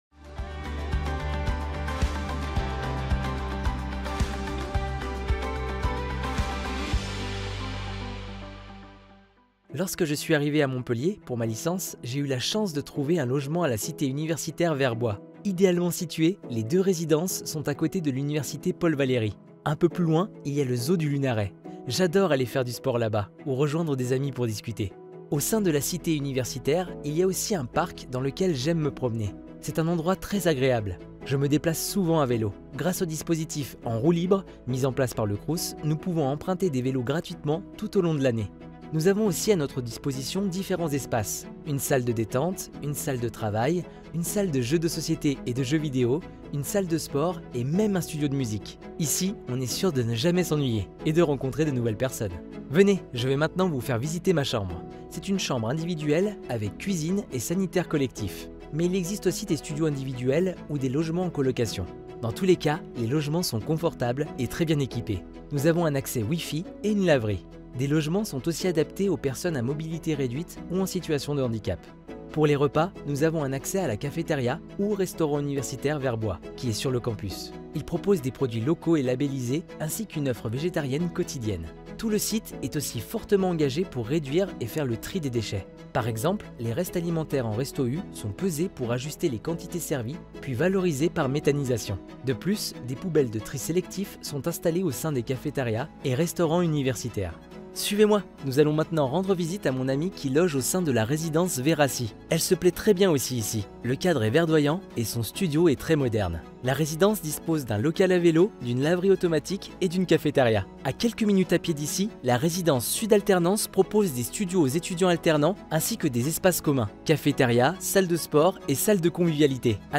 Young, Natural, Playful, Friendly
Explainer